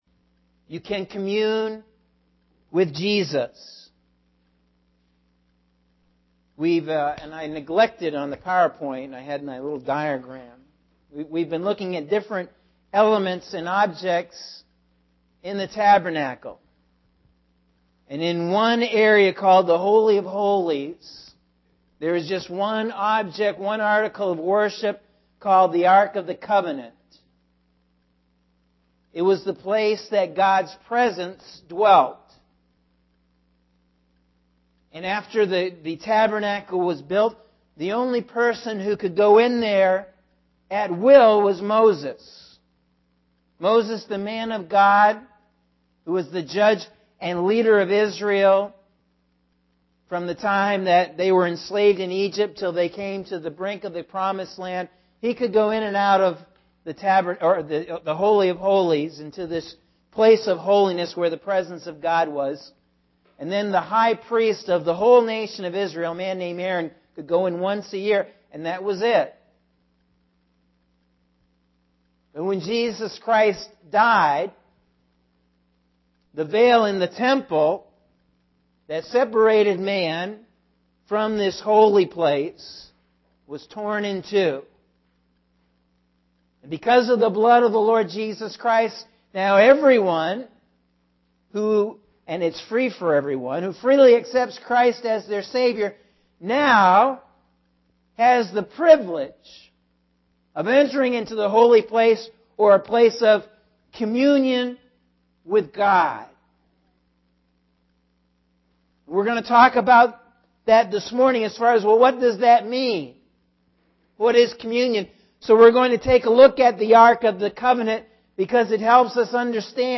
Sunday April 14th – AM Sermon – Norwich Assembly of God